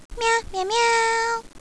Several Ryo-Ohki sounds!
Meoooow!
meowX3.wav